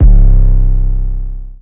808 (broke).wav